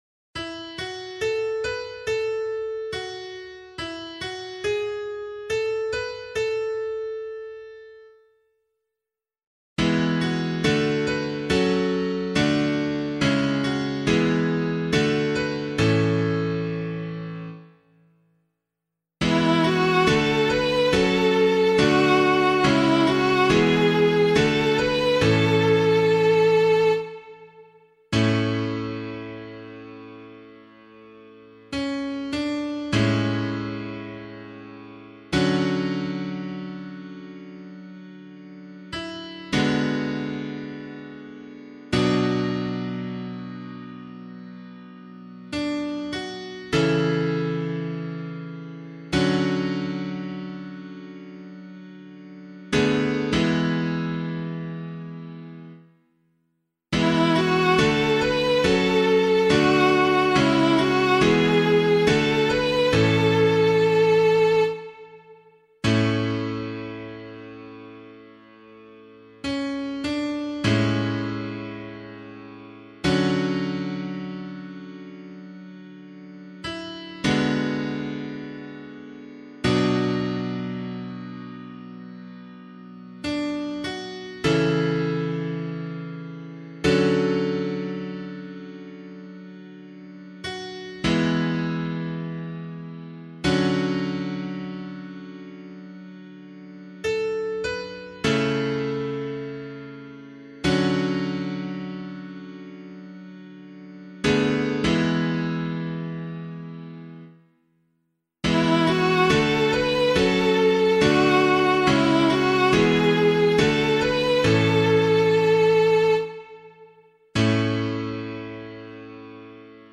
001 Advent 1 Psalm A [APC - LiturgyShare + Meinrad 8] - piano.mp3